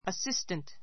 assistant A2 əsístənt ア スィ ス タン ト 名詞 ❶ 助手, アシスタント He worked as an assistant to his father [as his father's assistant].